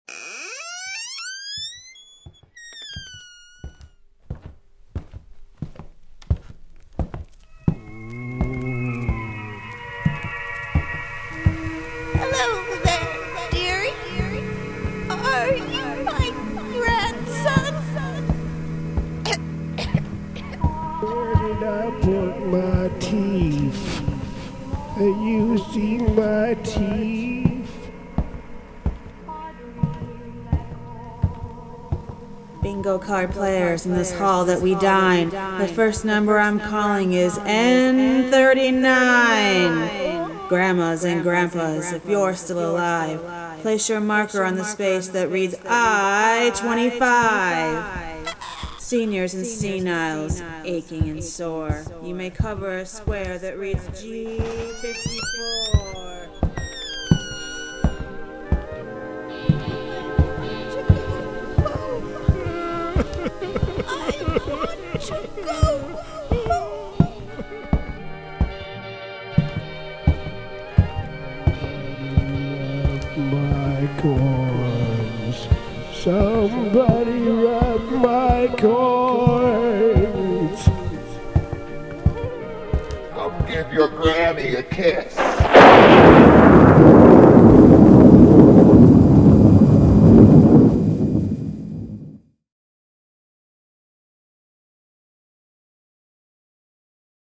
Take a disturbing gaze into your future as vacant-eyed wraiths reach toward your ride vehicle from their tiny rooms, the sickly smell of chemical disinfectent fills the air, and nonsensical cackles can be heard from behind closed doors.
To hear a sound clip recorded in the halls of The Retirement Manion, click on the play button below (or you can right click here to download an MP3 or just regular click to go to a page that will just play it.):